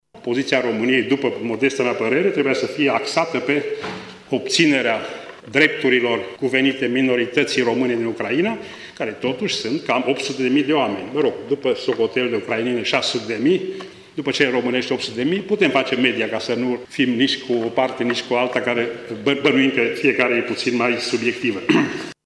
Declaraţia aparţine fostului ministru de externe Andrei Marga, care a susţinut, astăzi, o conferinţă la Universitatea „Petru Maior” din Tîrgu-Mureş.